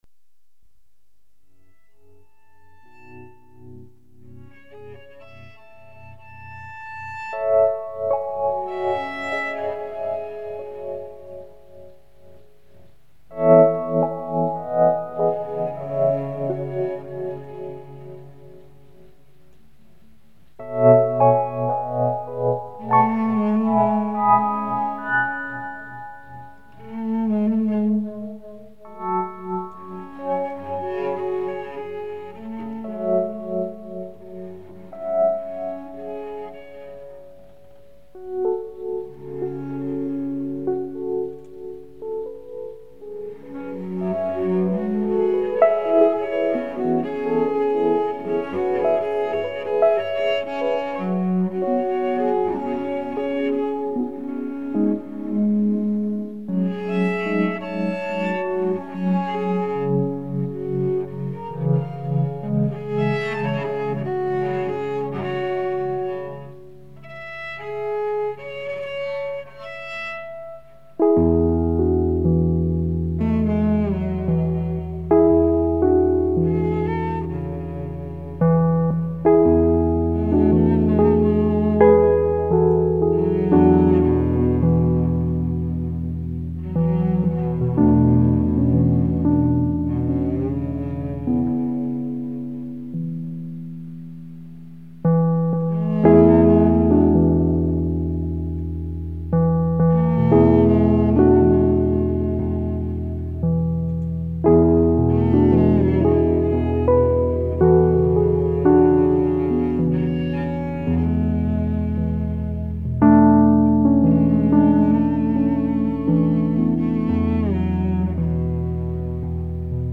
Double bass
Cello